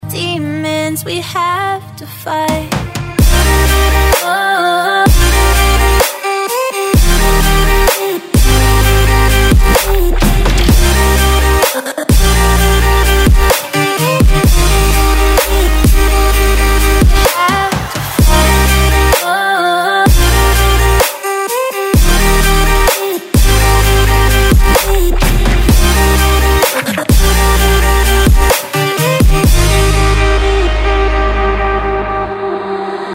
Категория: Trap рингтоны